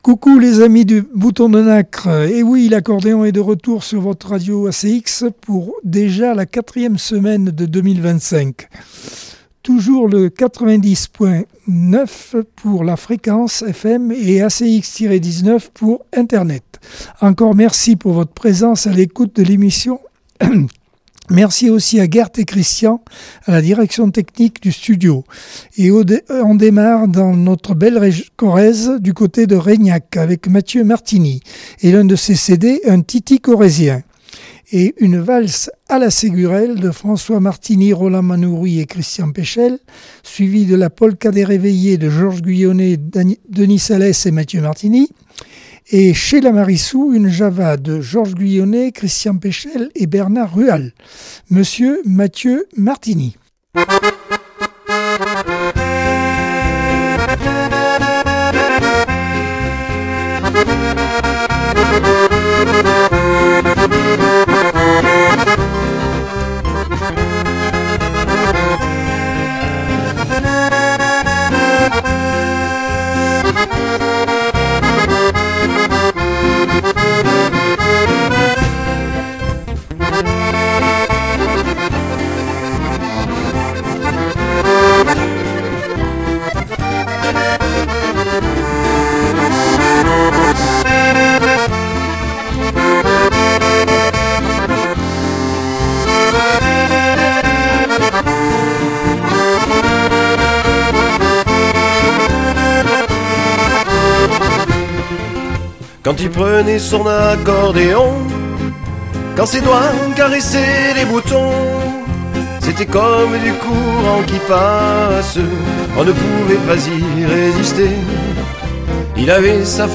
Accordeon 2025 sem 04 bloc 1 - Radio ACX